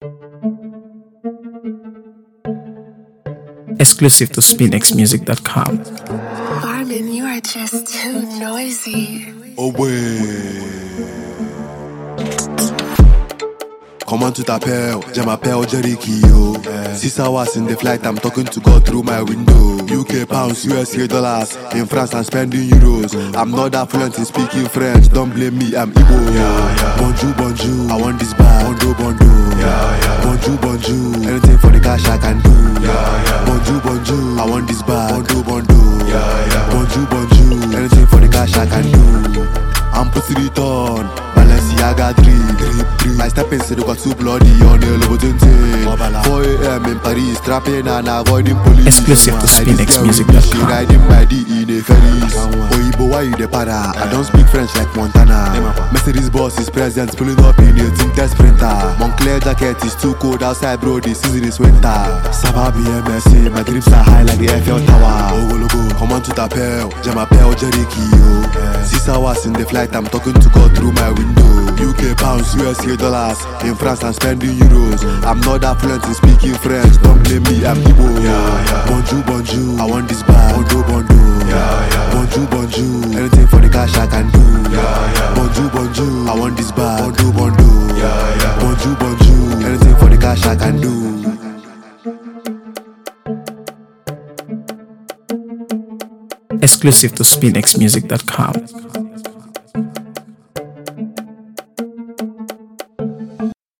AfroBeats | AfroBeats songs
Nigerian rapper and gifted singer